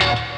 Raver Hit.wav